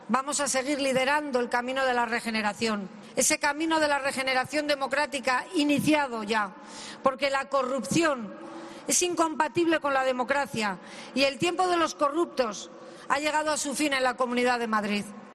"La corrupción es incompatible con la democracia, y el tiempo de los corruptos ha llegado a su fin en la Comunidad de Madrid", ha asegurado la presidenta regional en su discurso con motivo del Dos de Mayo, tras entregar las Medallas y Condecoraciones de la Comunidad.